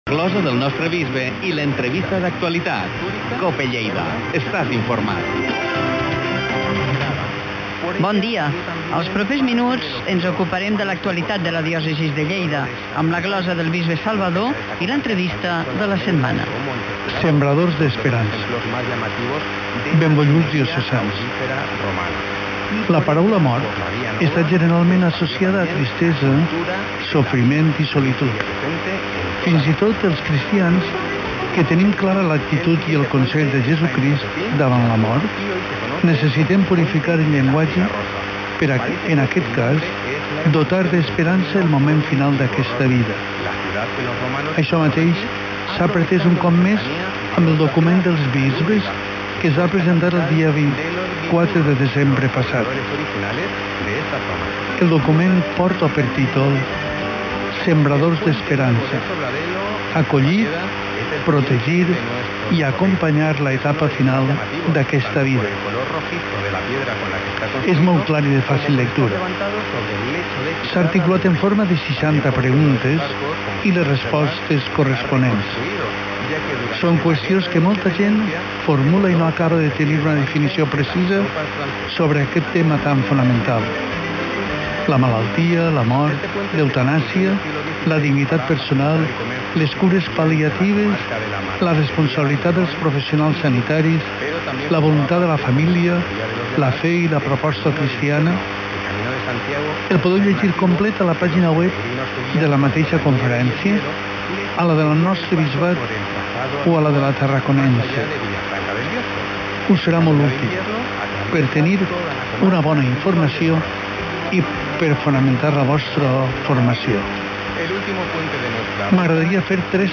ENTREVISTA SOBRE EL PELEGRINATGE A GRÈCIA SEGUINT LA RUTA DE SANT PAU